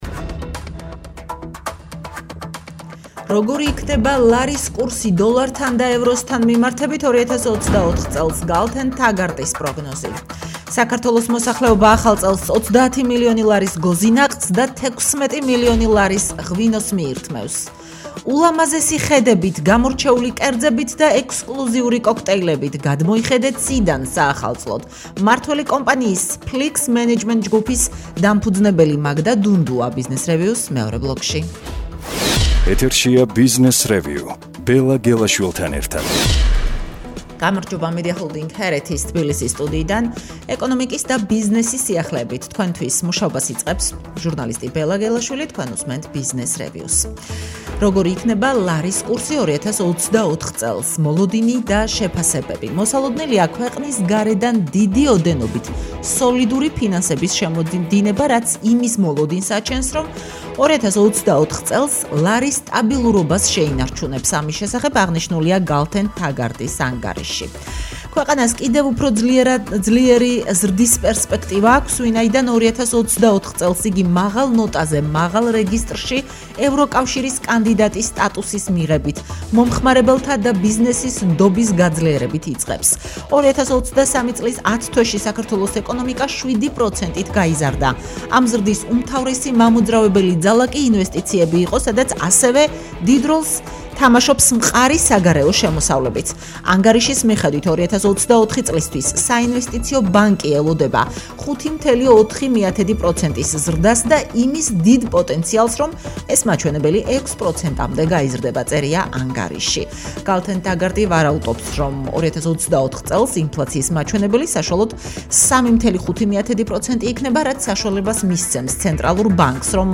ყოველდღღე რადიოების: თბილისის FM93.5, ჰერეთის FM102.8 და ციტრუსის FM97.3 ეთერში ორშაბათიდან პარასკევის ჩათვლით 10:10 და 21:00 საათზე.